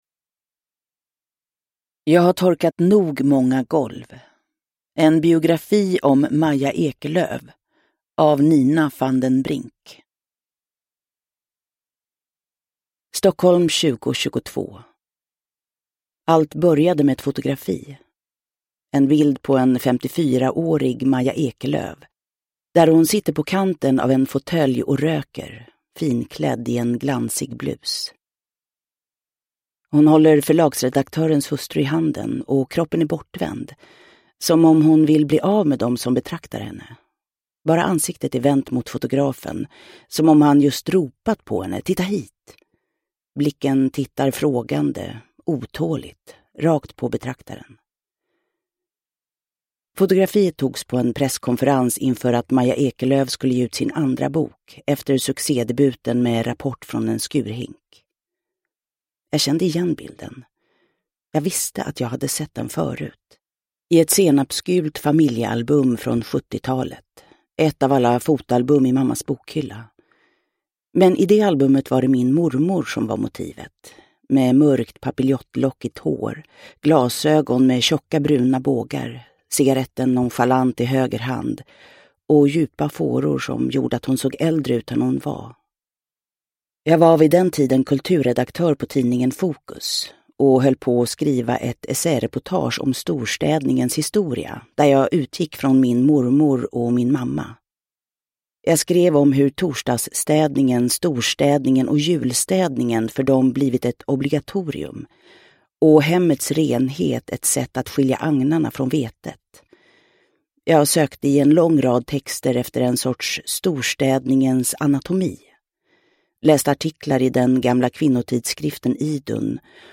Produkttyp: Digitala böcker
Uppläsare: Jessica Liedberg